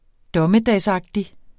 Udtale [ ˈdʌməˌdæˀsˌɑgdi ]